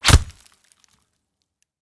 knife_hitwall4.wav